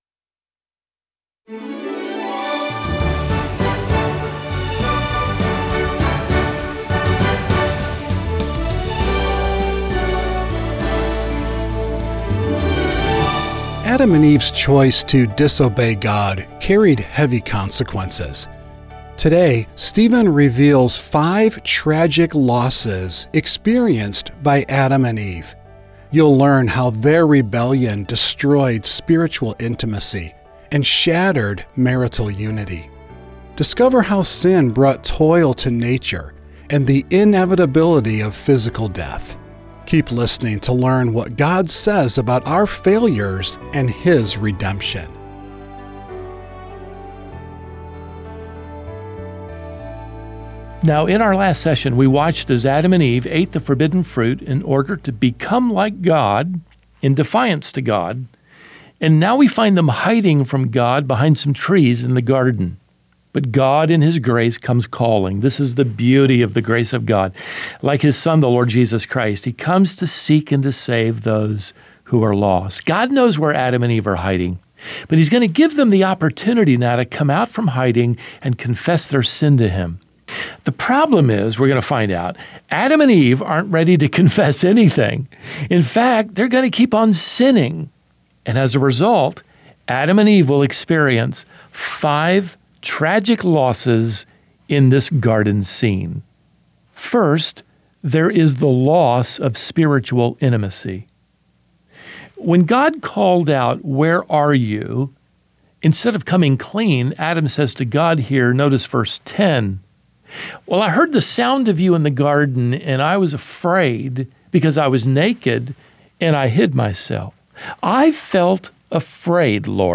a three-year journey through the entire Bible, Genesis to Revelation, with one 10-minute lesson each weekday.